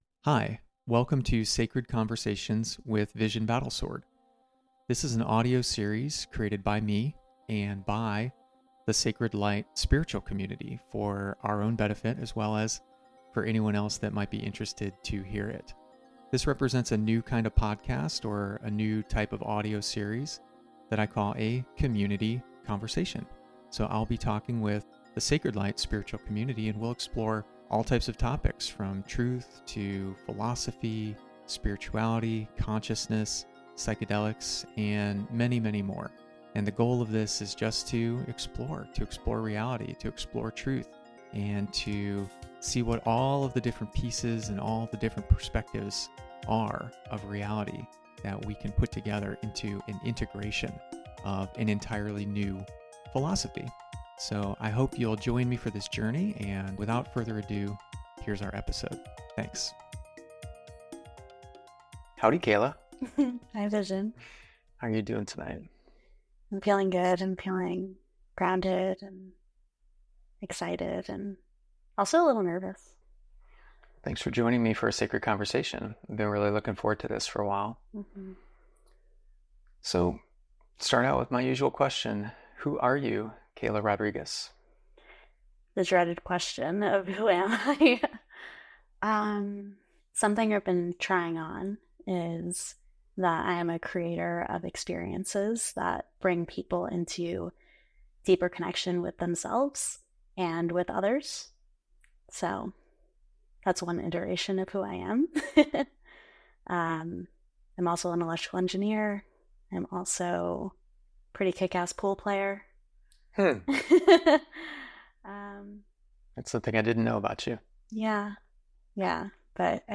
Join us for a transformative dialogue that will leave you seeing consent as the bedrock of trust and the keystone of true connection.
conversation08-consent.mp3